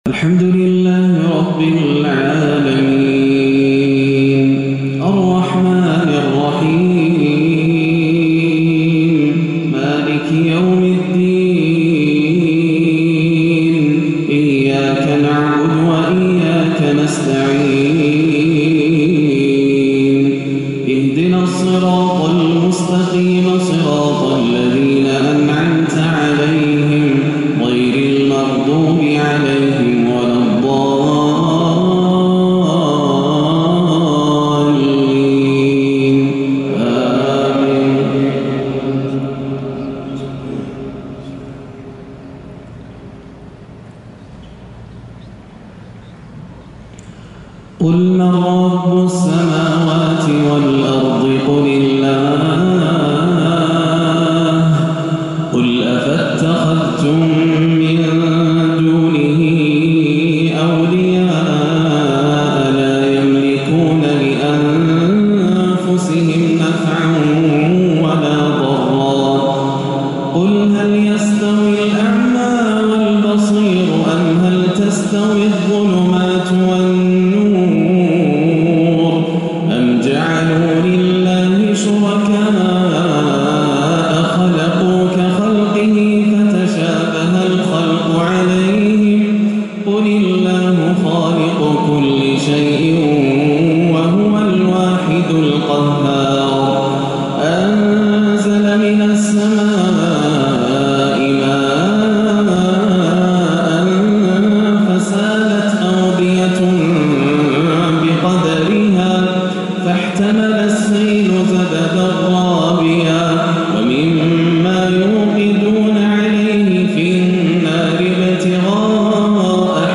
(أَلَا بِذِكْرِ اللَّهِ تَطْمَئِنُّ الْقُلُوبُ) تلاوة عراقية لا توصف من سورة الرعد - 18-7 > عام 1437 > الفروض - تلاوات ياسر الدوسري